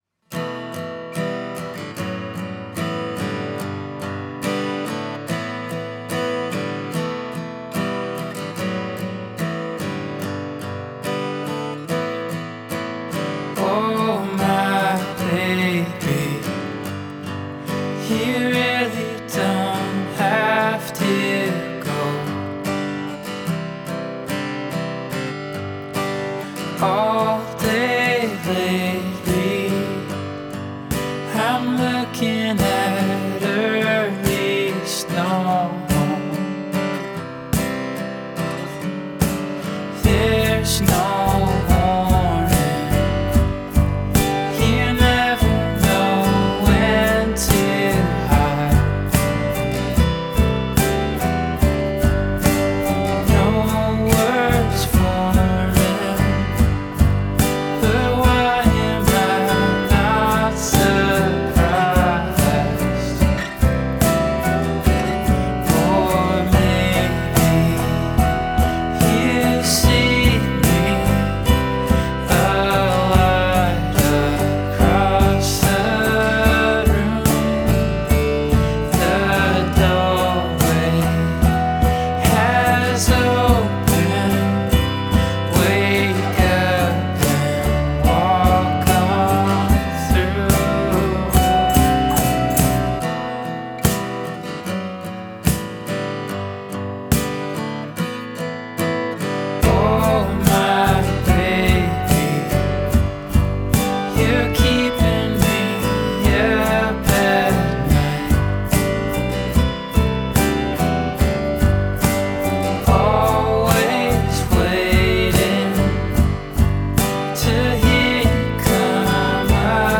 contemporary folk musician